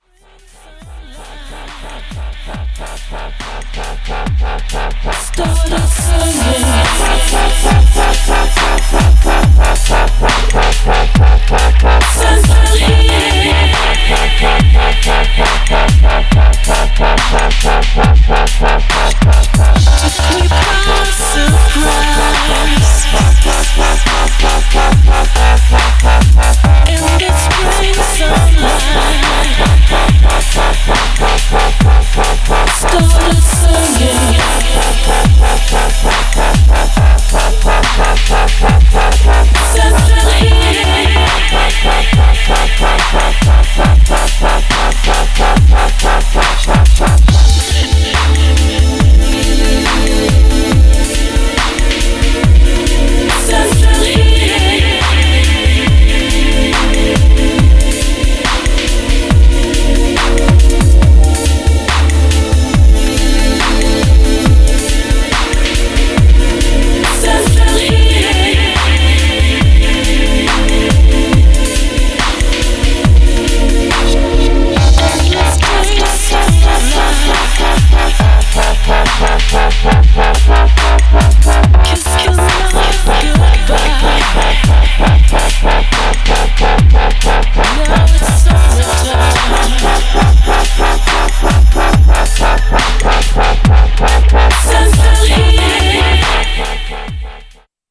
Styl: Dub/Dubstep, Drum'n'bass, Breaks/Breakbeat